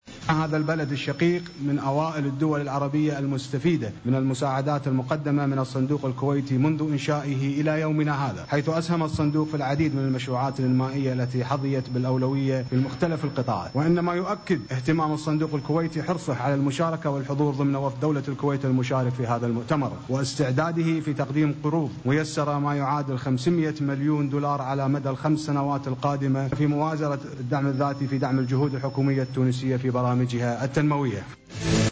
أعلن وزير المالية الكويتي أنس خالد الصالح اليوم خلال كلمة ألقاها في افتتاح مؤتمر دعم الاقتصاد والاستثمار "تونس 2020" استعداد الكويت تقديم قروض ميسرة الى تونس قيمتها 500 مليون دولار خلال السنوات الخمس القادمة.